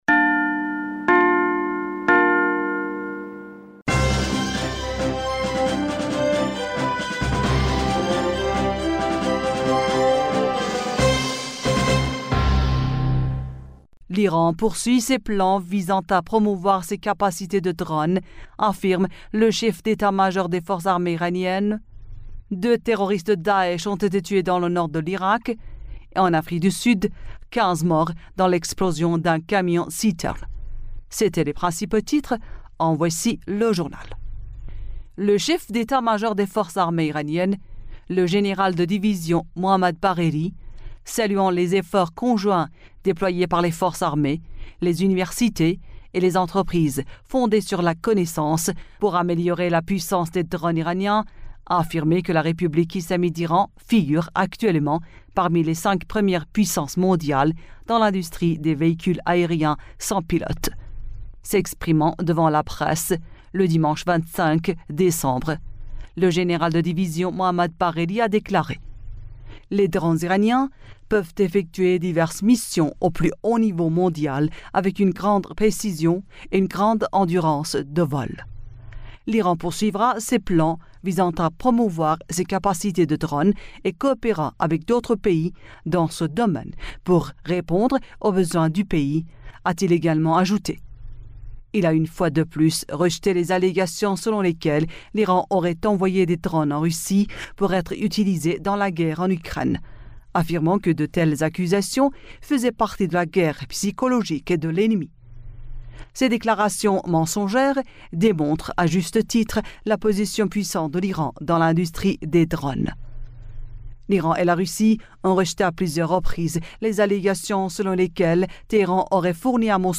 Bulletin d'information du 26 Décembre